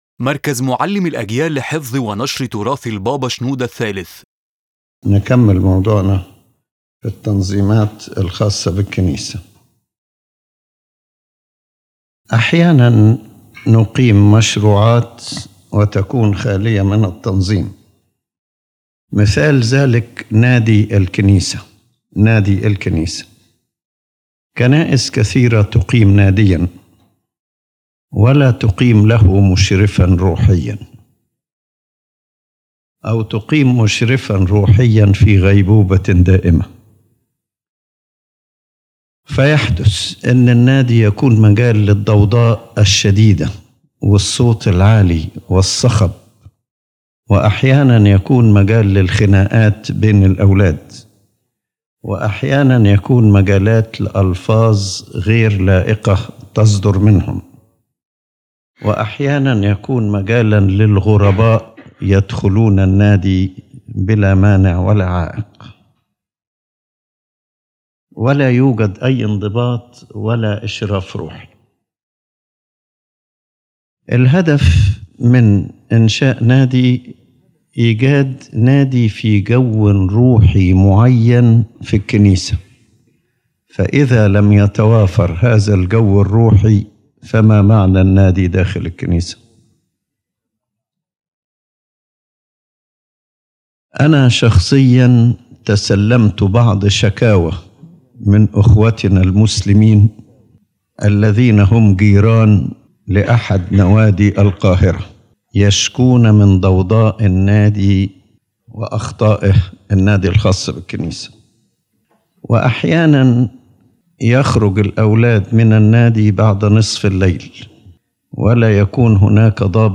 His Holiness Pope Shenouda III continues his teaching on the importance of order inside the Church, emphasizing that lack of organization leads to loss of spirituality, spread of disorder, and stumbling many both inside and outside the Church. Order is not merely administrative; it is a spiritual necessity that preserves holiness and organizes all ministry.